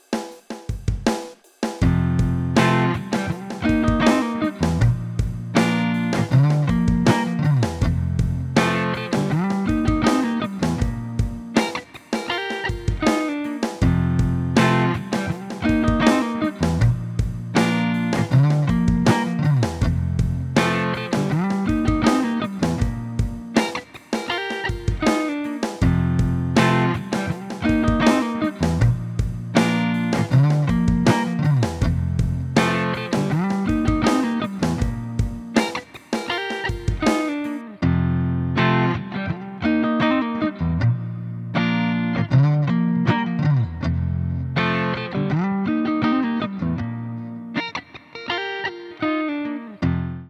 Jego charakterystyka brzmieniowa opiera się na przyjemnej jasnej i mocnej górze.
• tradycyjny humbucker
• bardzo klarowne brzmienie; idealne do przebiegów i artykulacji jazzowej